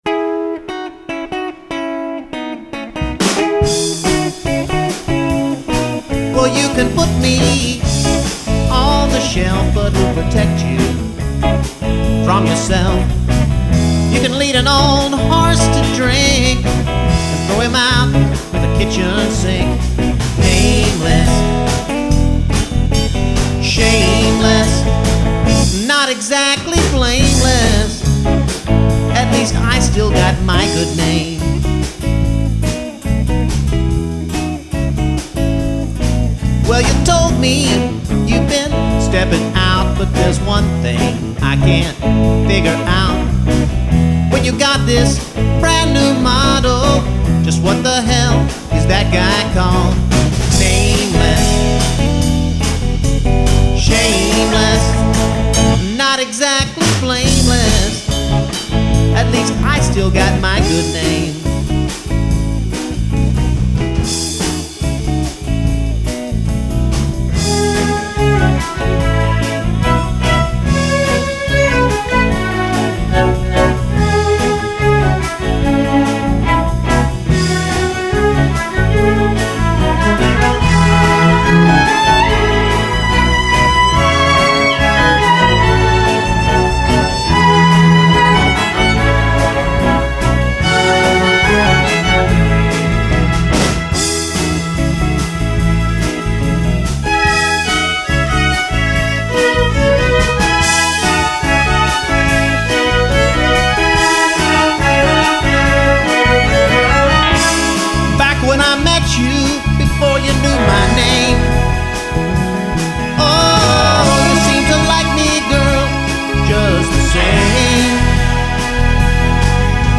The strings are probably mixed too loud.